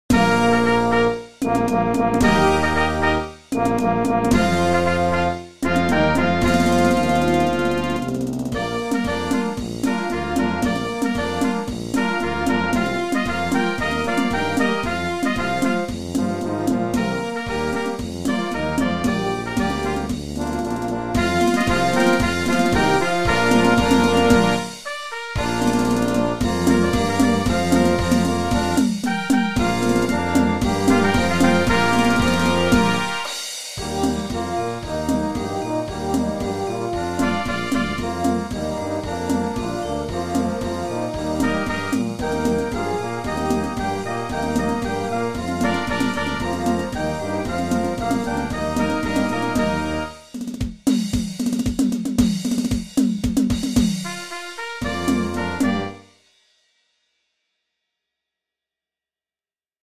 marche pour Batterie-Fanfare